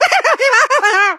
gene_vo_12.ogg